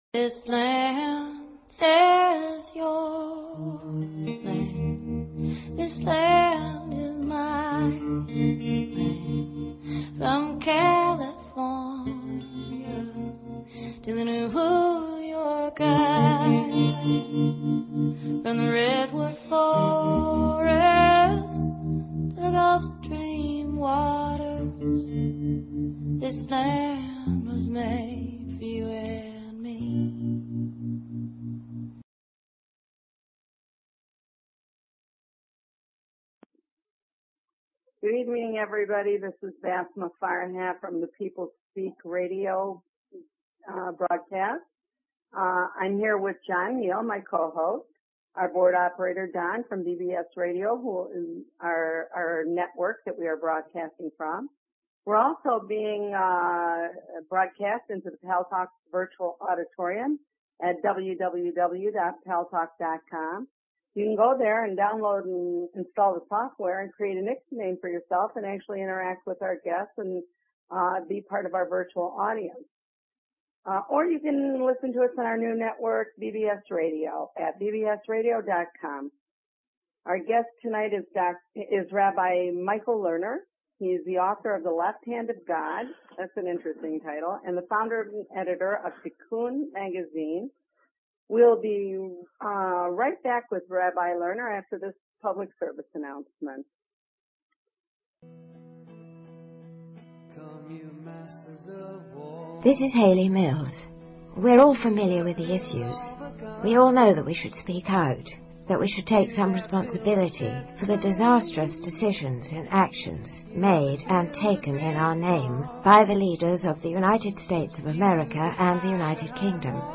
Guest, Rabbi Michael Lerner